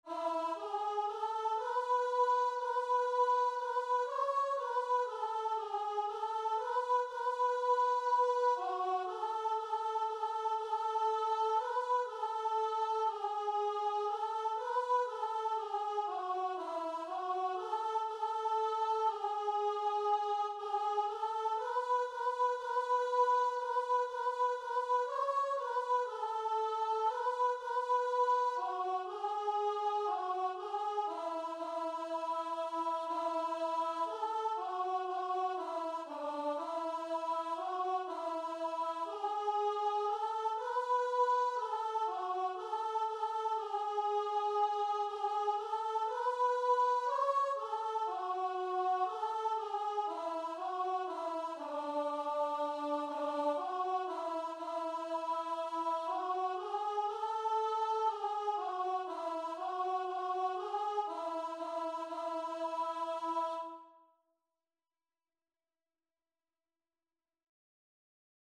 Free Sheet music for Voice
Traditional Music of unknown author.
A major (Sounding Pitch) (View more A major Music for Voice )
4/4 (View more 4/4 Music)
Christian (View more Christian Voice Music)